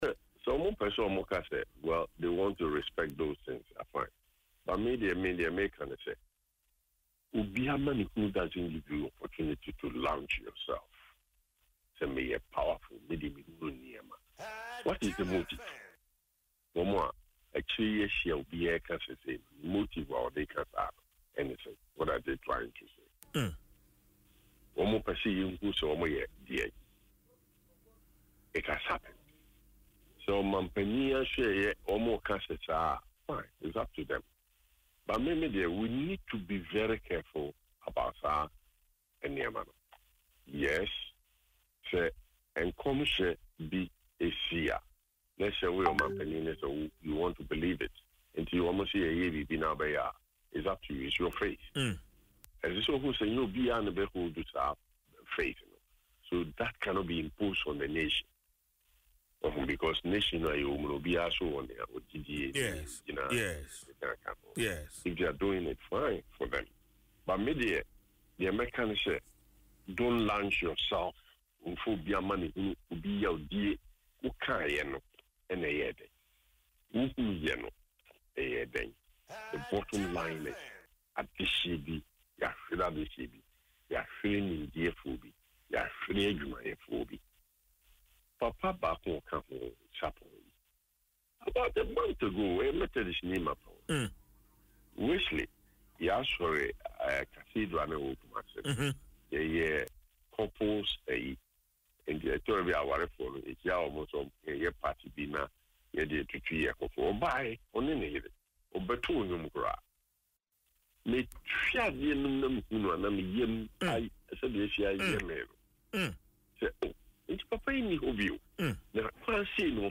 Speaking on Adom FM’s Dwaso Nsem, Prof. Asante warned against exploiting national tragedies to showcase spiritual power or for personal gain.